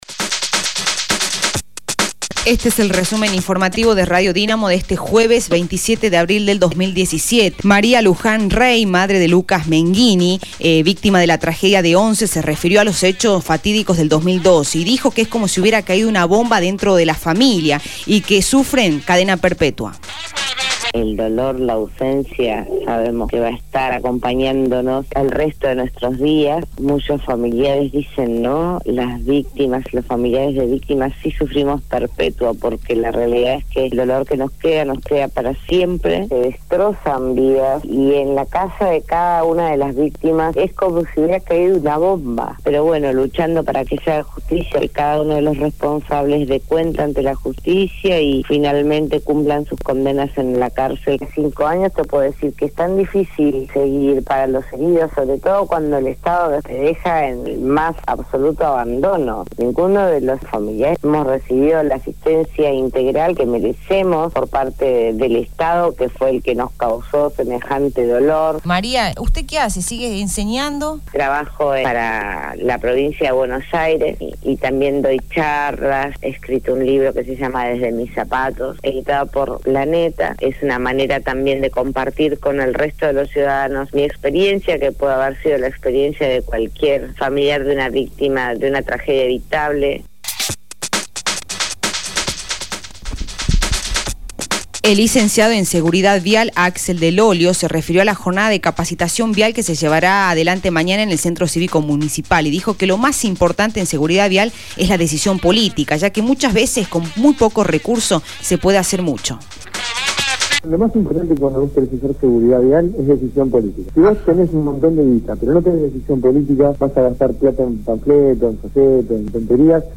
Resumen Informativo de Radio Dinamo del día 27/04/2017 2° Edición